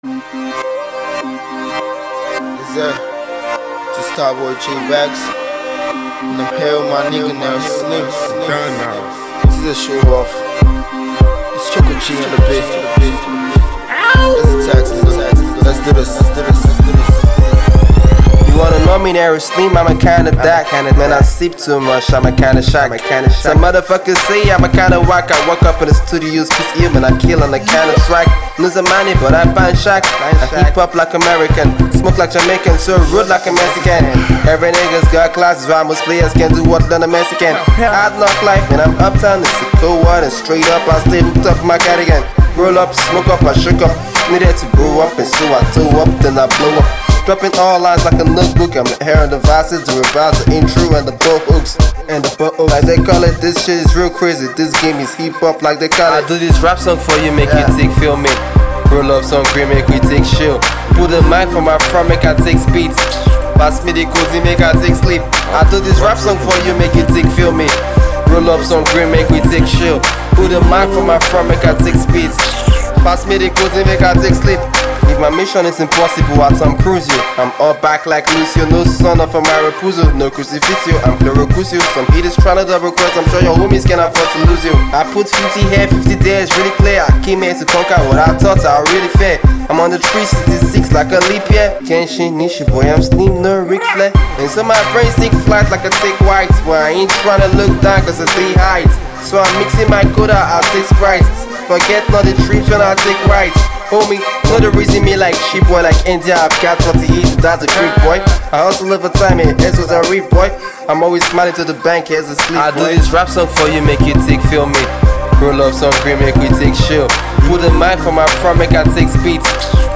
Rap song